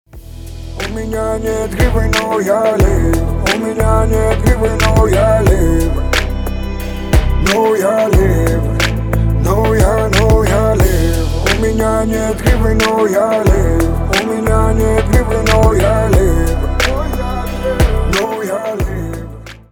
• Качество: 320, Stereo
русский рэп
спокойные